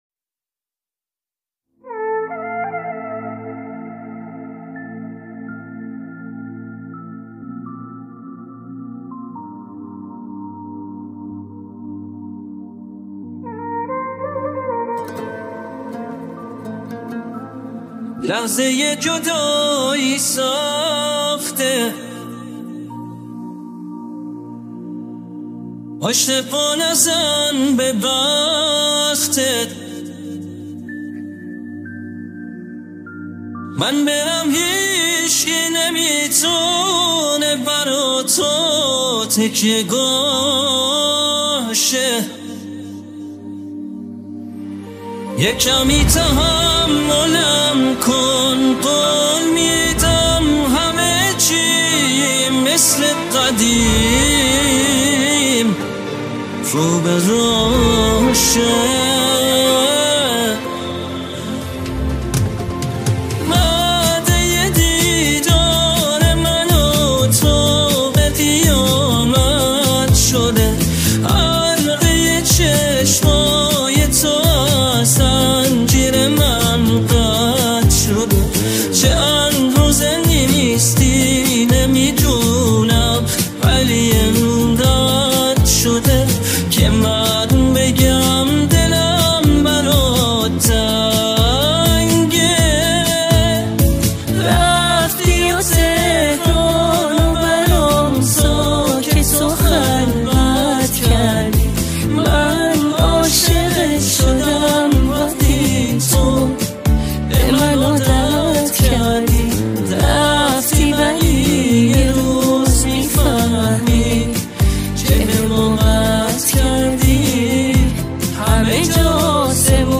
این آهنگ توسط هوش مصنوعی ساخته شده است.